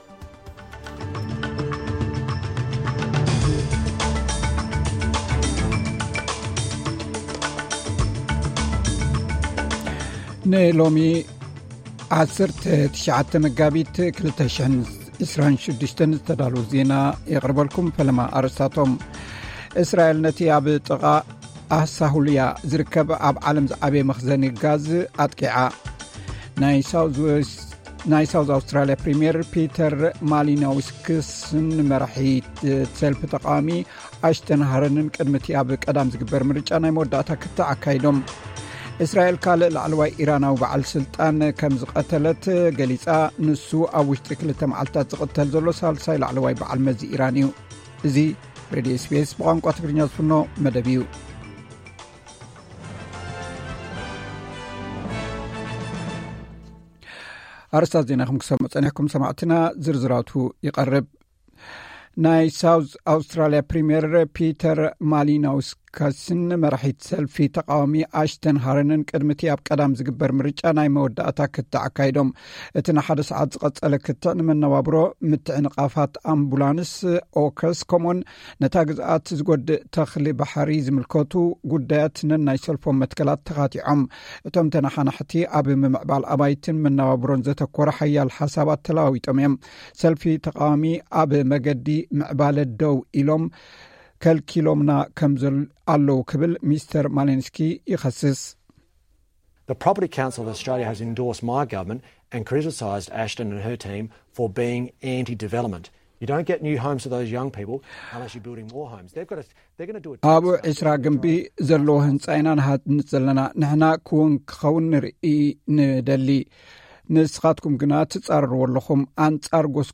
ዕለታዊ ዜና SBS ትግርኛ (19 መጋቢት 2026)